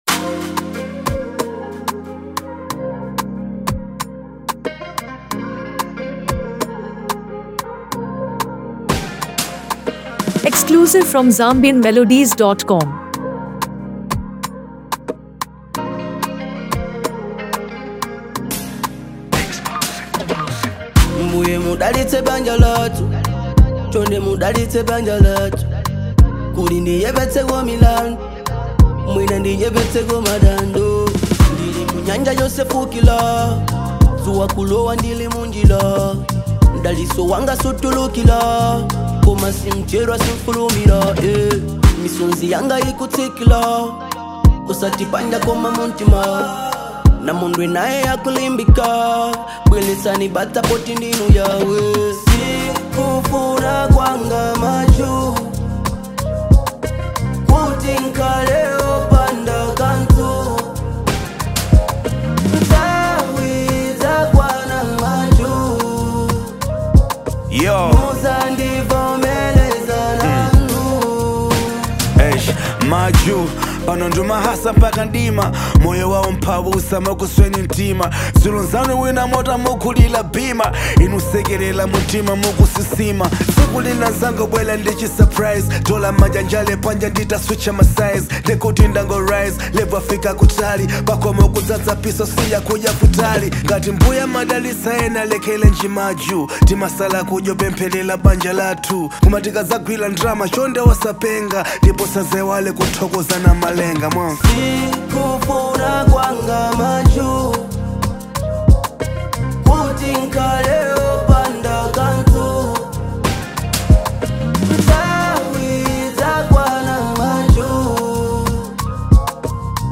praised for its danceable beat, engaging hook